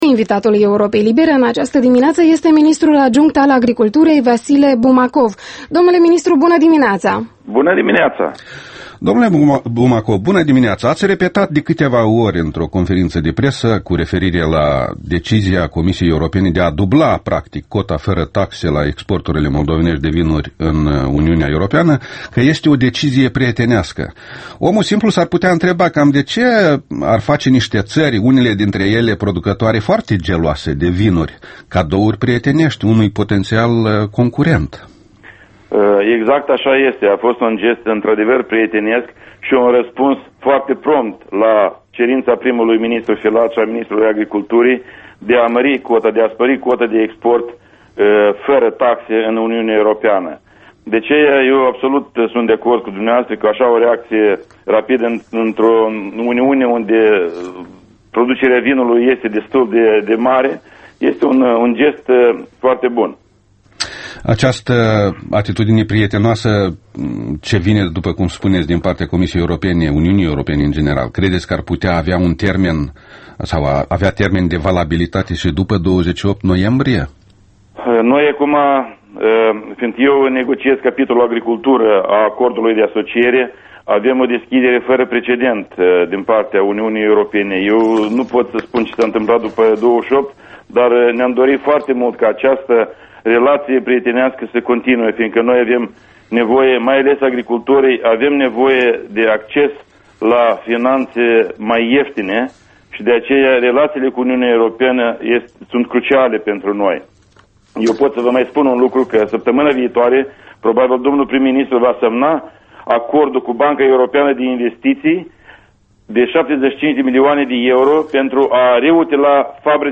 Interviul matinal EL: cu Vasile Bumacov